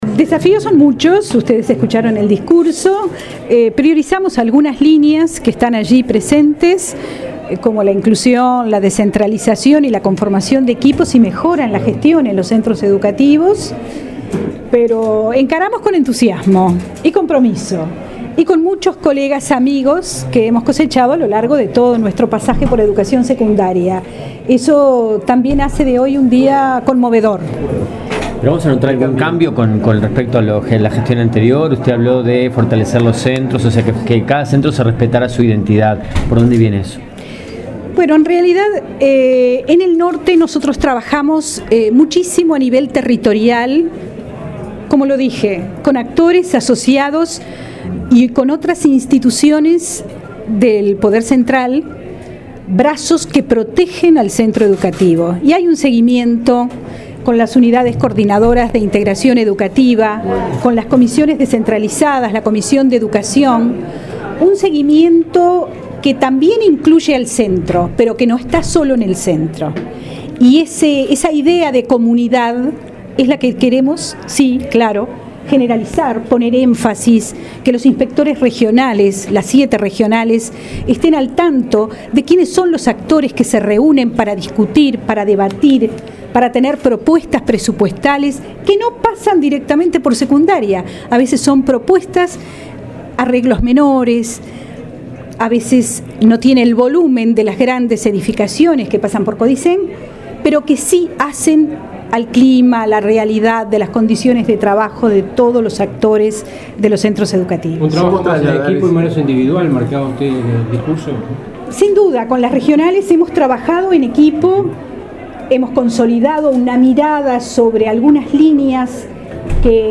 “Exhortamos a padres y actores de la educación a acompañarnos en este camino”, afirmó la nueva directora de Secundaría, Ana Olivera, en el acto de asunción al cargo. Aseguró que se hará énfasis en la inclusión y protección de trayectorias educativas y se profundizará los procesos de mejora de estrategias de institucionalización para retener a los estudiantes en el sistema educativo.